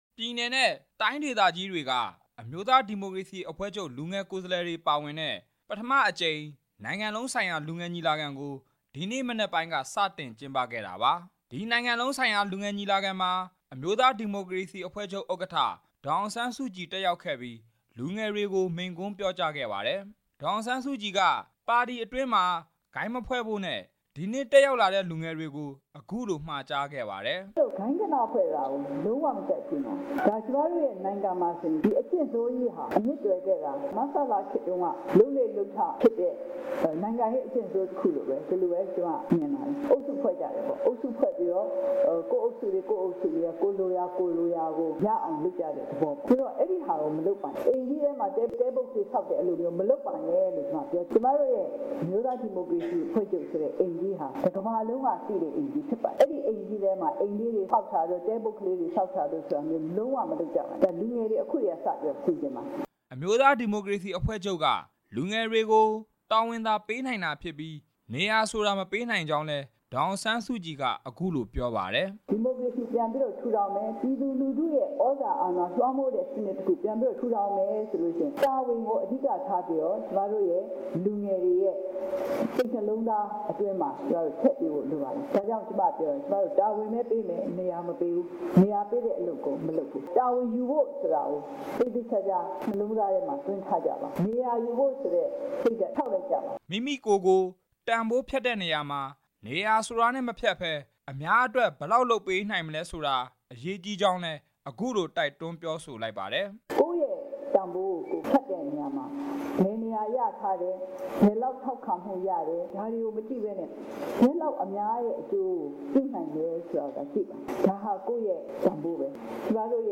dassk-nld-meeting-305 ဒီနေ့မနက်က ကျင်းပတဲ့ အမျိုးသားဒီမိုကရေစီ အဖွဲ့ချုပ် ပထမအကြိမ် နိုင်ငံလုံးဆိုင်ရာ လူငယ်ညီလာခံကို အမျိုးသားဒီမိုကရေစီ အဖွဲ့ချုပ် ဥက္ကဌ ဒေါ်အောင်ဆန်းစုကြည် တက်ရောက် မိန့်ခွန်းပြောကြားခဲ့ပါတယ်။
NLD အမျိုးသားဒီမိုကရေစီ အဖွဲ့ချုပ် ပထမအကြိမ် နိုင်ငံ လုံးဆိုင်ရာ လူငယ်ညီလာခံကို ရန်ကုန်မြို့ ဗဟန်း မြို့နယ် ရွှေဂုံတိုင်က  တော်ဝင်နှင်းဆီခန်းမမှာ ဒီနေ့ မနက်က ကျင်းပခဲ့ပါတယ်။